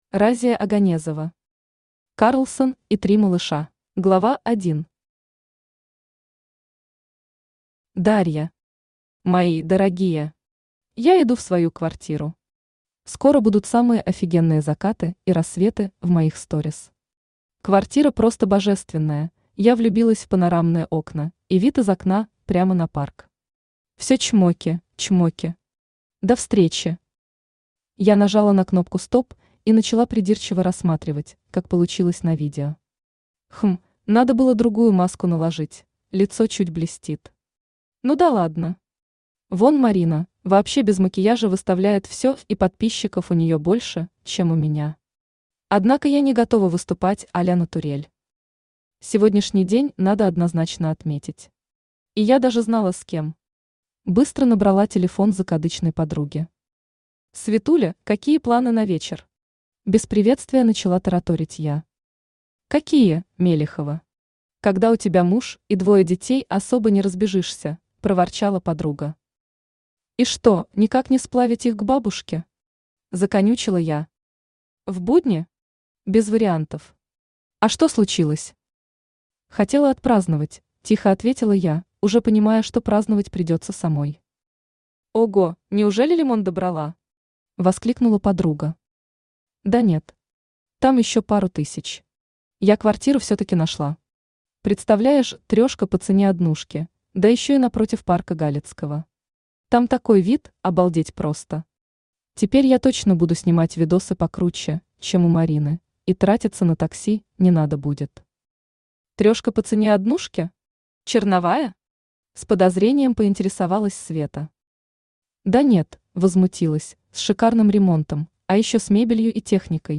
Аудиокнига Карлсон и три малыша | Библиотека аудиокниг
Aудиокнига Карлсон и три малыша Автор Разия Оганезова Читает аудиокнигу Авточтец ЛитРес.